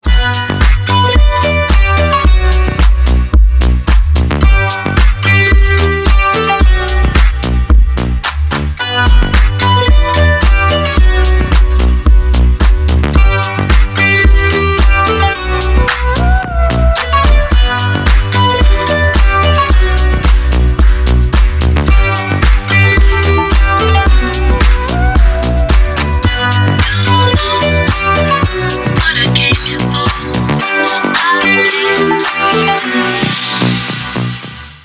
deep house
dance
club